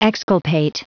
Prononciation du mot exculpate en anglais (fichier audio)
Prononciation du mot : exculpate
exculpate.wav